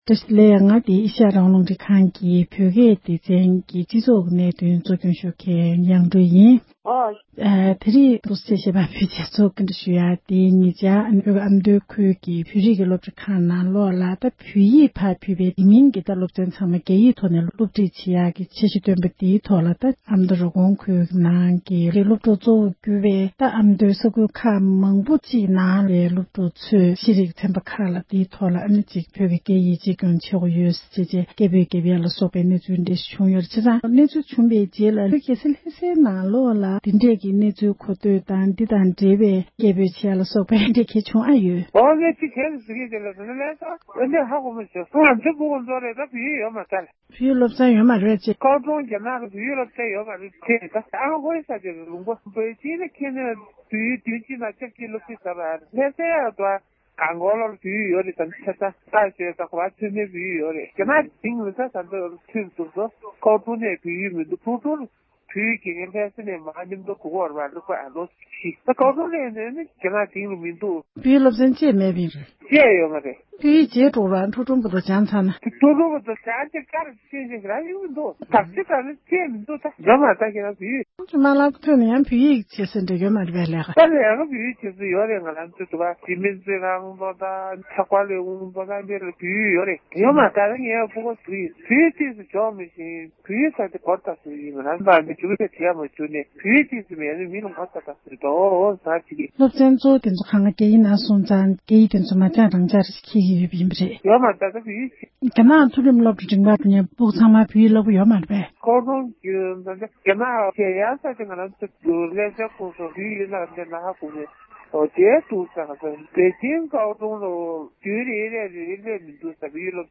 བོད་ཀྱི་རྒྱལ་ས་ལྷ་སའི་འབྲེལ་ཡོད་མི་སྣ་ཞིག་ལ་བཀའ་འདྲི་ཞུས་པ་ཞིག་ལ་གསན་རོགས་གནོངས༎